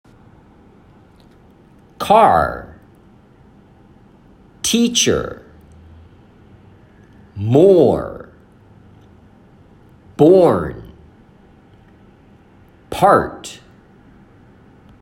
アメリカ式では母音の後のRも発音
なお、それぞれの発音は以下のとおりです。
Car-Teacher-More-Born-Part.m4a